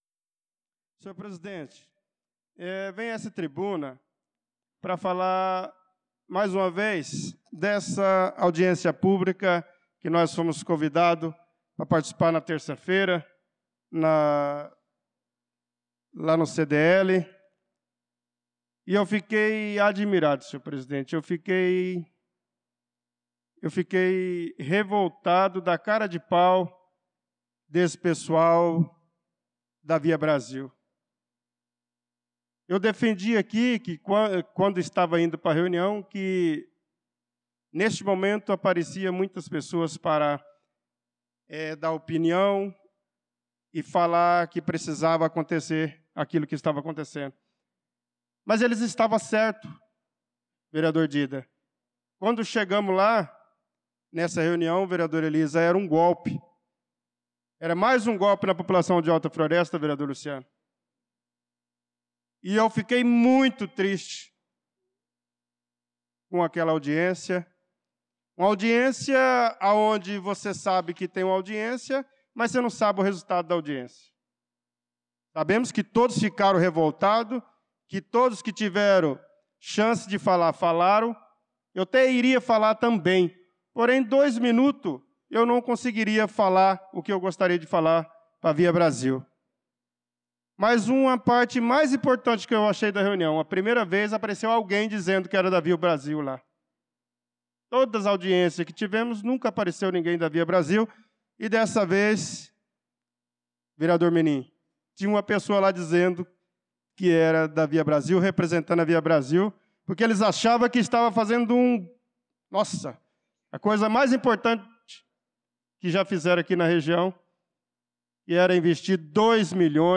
Pronunciamento do vereador Bernardo Patrício na Sessão Ordinária do dia 25/03/2025 — Câmara Municipal